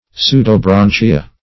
Search Result for " pseudobranchia" : The Collaborative International Dictionary of English v.0.48: Pseudobranchia \Pseu`do*bran"chi*a\, n.; pl.
pseudobranchia.mp3